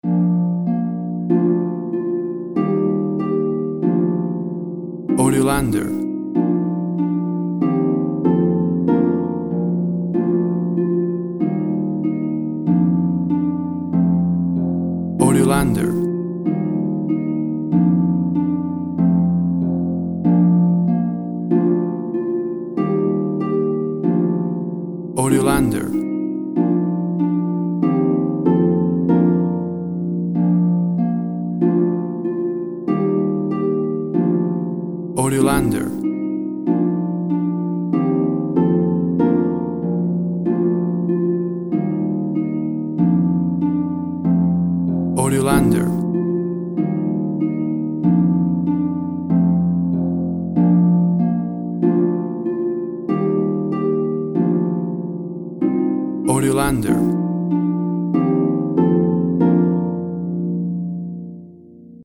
WAV Sample Rate 16-Bit Stereo, 44.1 kHz
Tempo (BPM) 95